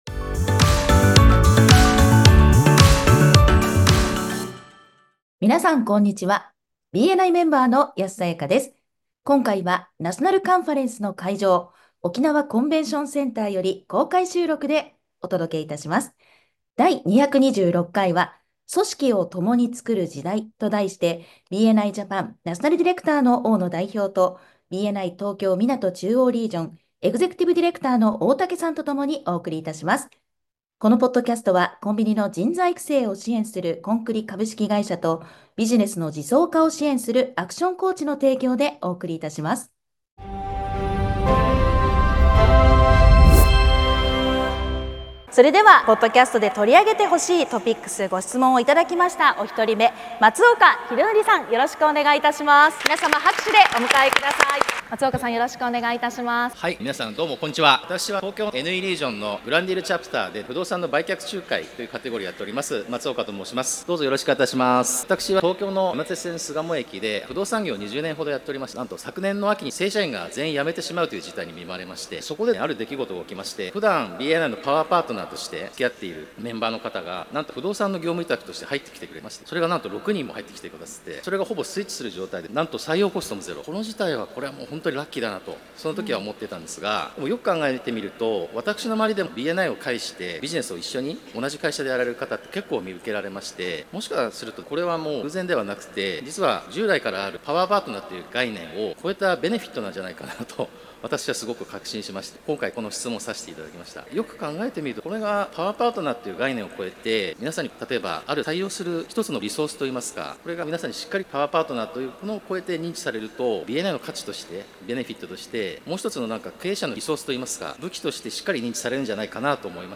今回はナショナルカンファレンスの会場、沖縄コンベンションセンターより、公開収録でお届けいたします。